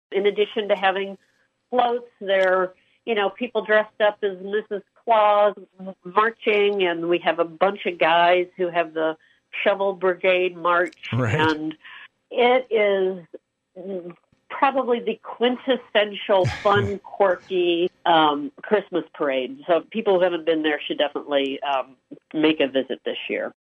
Saugatuck Mayor Holly Anderson is inviting everyone.